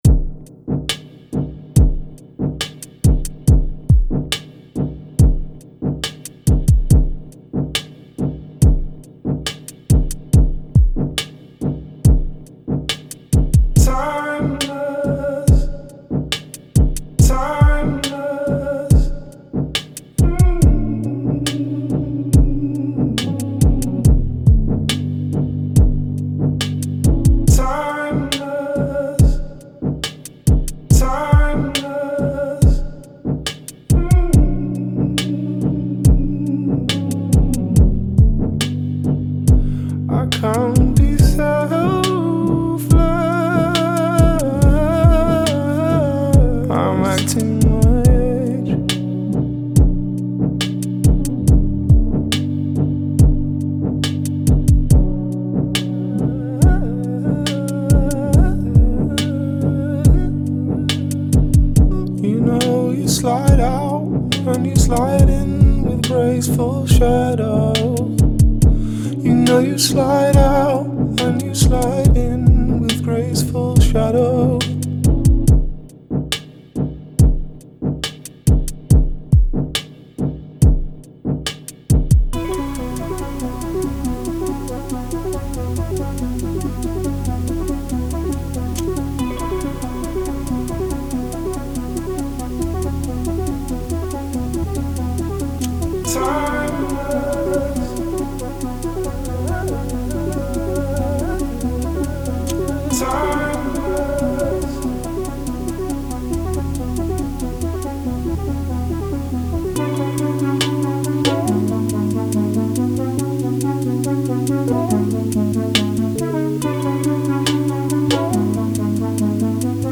electronic music